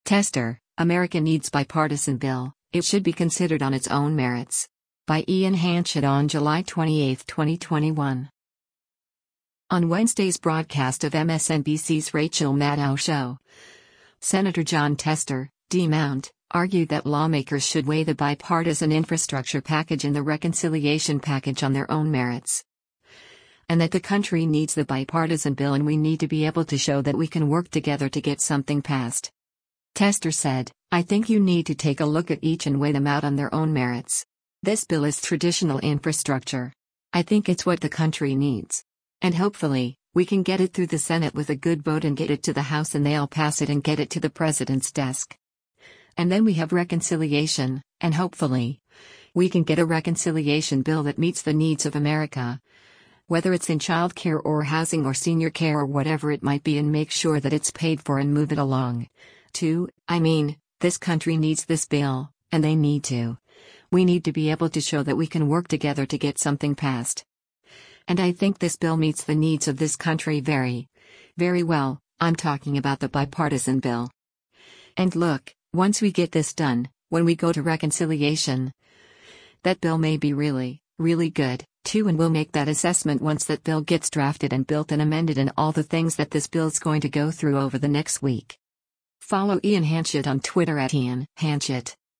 On Wednesday’s broadcast of MSNBC’s “Rachel Maddow Show,” Sen. Jon Tester (D-MT) argued that lawmakers should weigh the bipartisan infrastructure package and the reconciliation package “on their own merits.” And that the country “needs” the bipartisan bill and “we need to be able to show that we can work together to get something passed.”